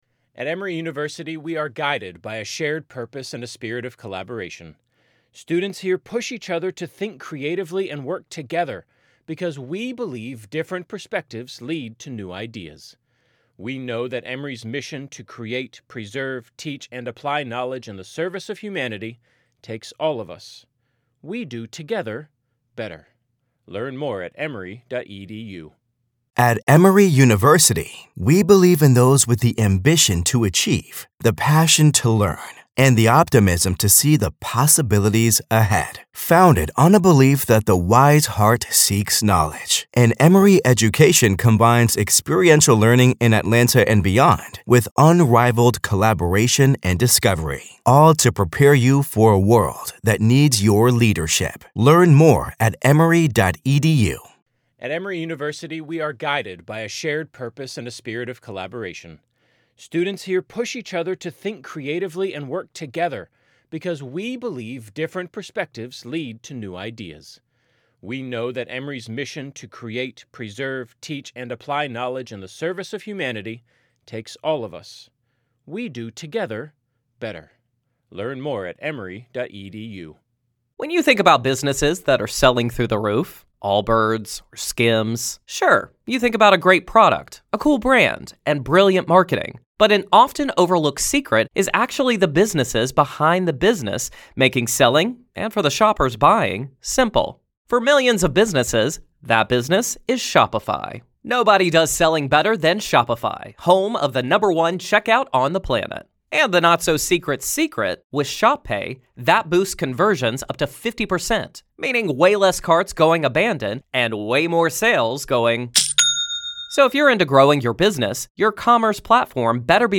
The hosts break down each claim, exposing how Daybell recasts traumatic events as supernatural confirmation of his “prophetic” status.
With humor, skepticism, and sharp analysis, the conversation pulls apart the contradictions.